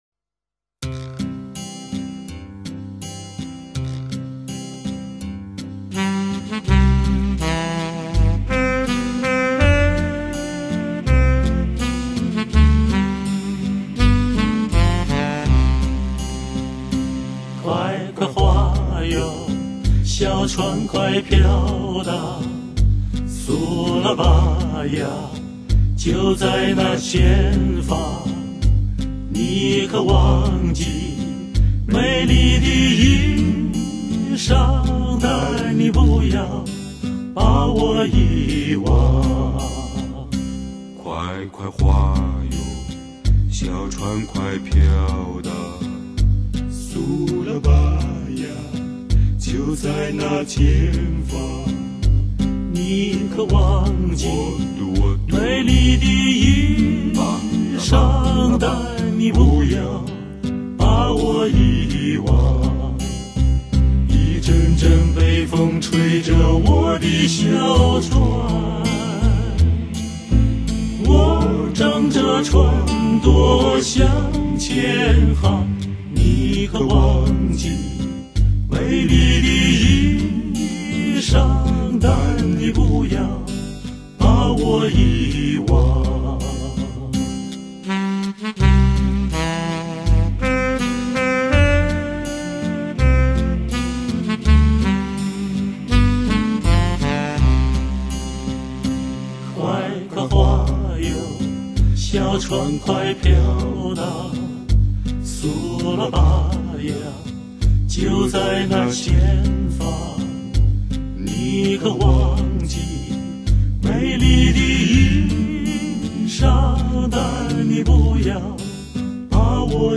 青年男女歌唱家、重唱珠联璧合、唯美专业典范、中国民歌与苏联民歌、交相辉映、璀璨多姿迷情。
女中音飘逸和美,男中音浑厚宏雄,沿着一条清幽的歌声小路,寻回我们珍藏已久的心境。